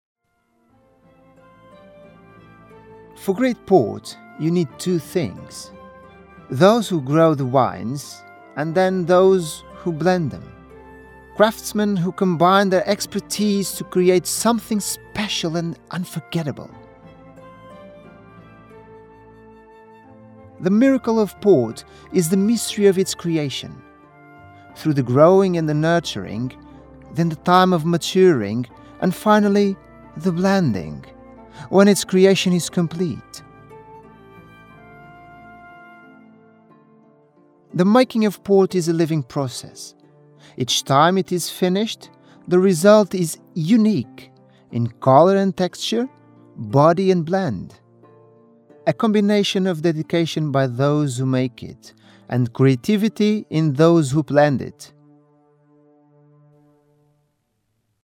Portuguese, Male, 30s-40s